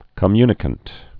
(kə-mynĭ-kənt)